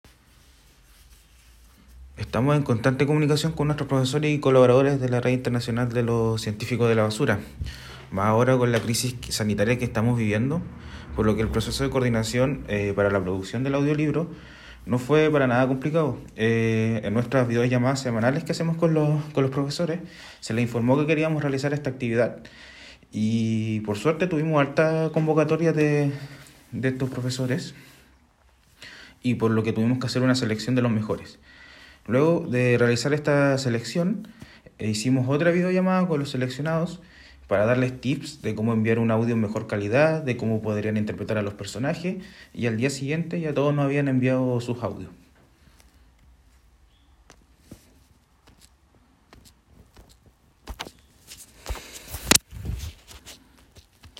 Integrantes de red latinoamericana ambiental colaboran en audiolibro para niños
Científicos de la Basura del Pacífico prestaron sus voces para grabar esta historia que motiva al cuidado de los océanos, a través de las aventuras de tres tortugas marinas.